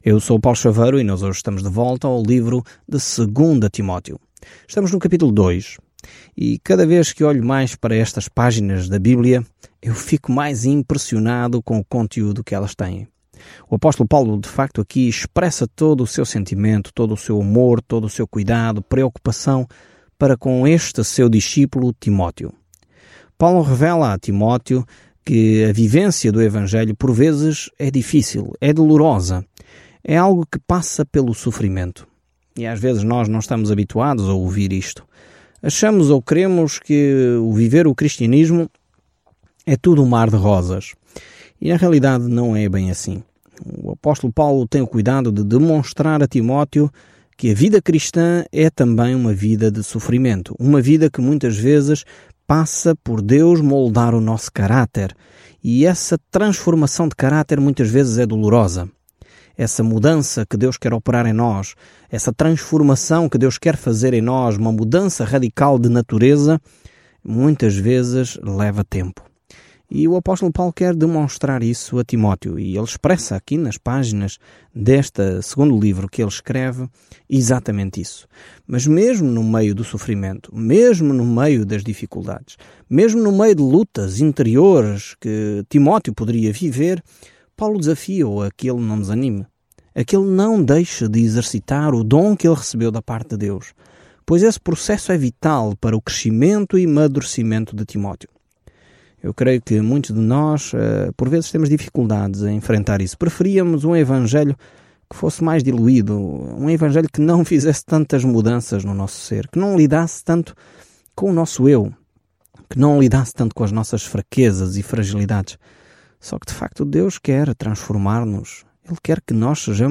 Scripture 2 Timothy 2:1-13 Day 2 Start this Plan Day 4 About this Plan A segunda carta a Timóteo exorta o povo de Deus a defender a palavra de Deus, a guardá-la, pregá-la e, se necessário, sofrer por ela. Viaje diariamente por 2 Timóteo enquanto ouve o estudo em áudio e lê versículos selecionados da palavra de Deus.